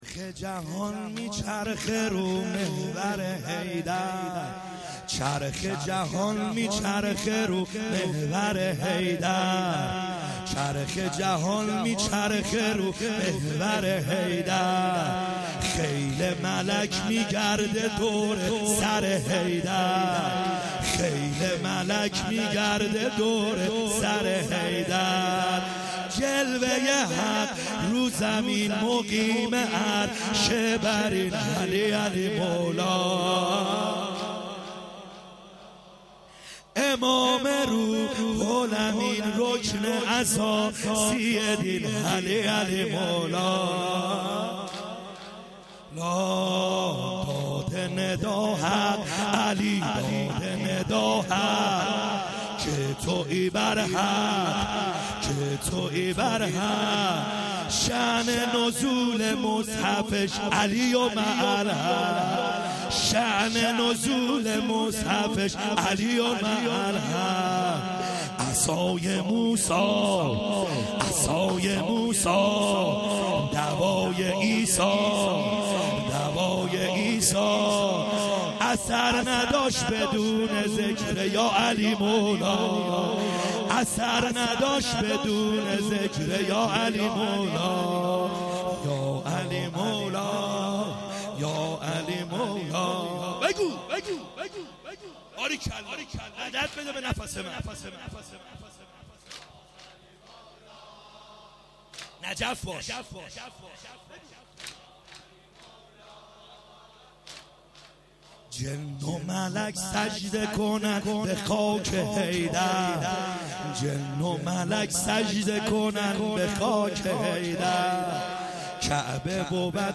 خیمه گاه - بیرق معظم محبین حضرت صاحب الزمان(عج) - واحد | چرخ جهان میچرخه